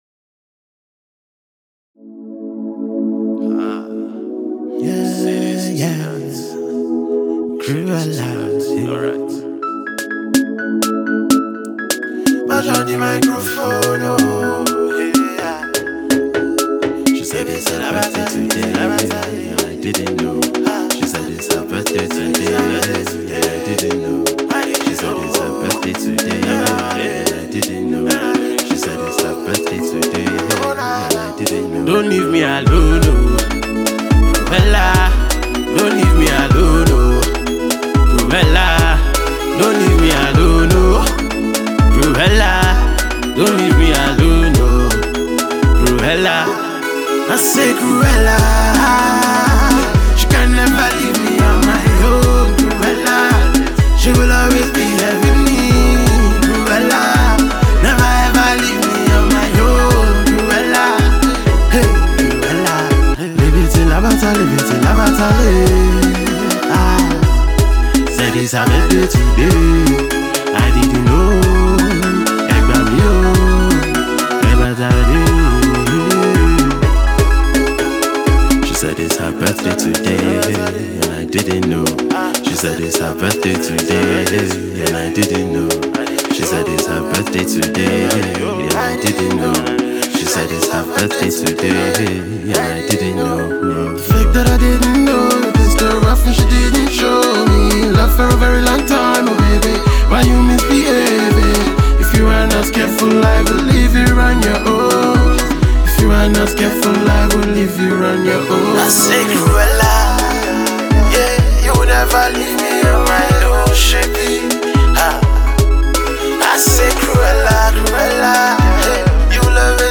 hit-making husky crooner of the Nigerian music industry